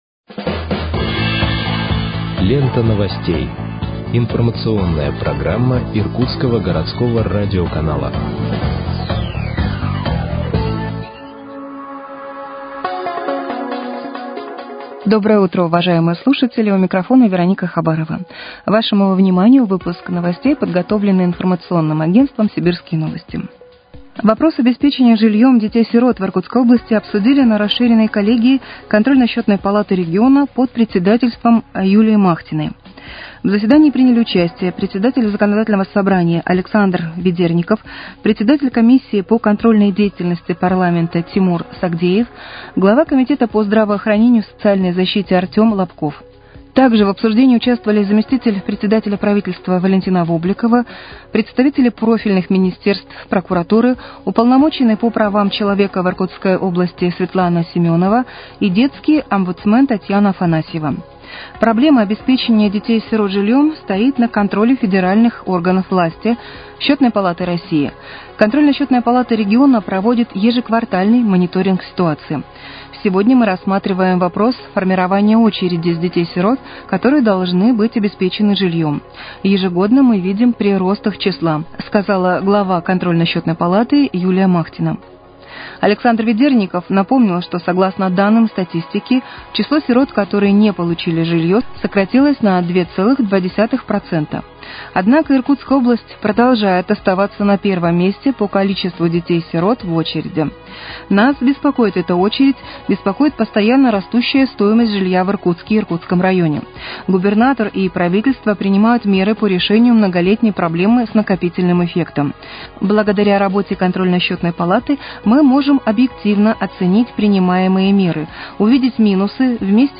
Выпуск новостей в подкастах газеты «Иркутск» от 30.10.2024 № 1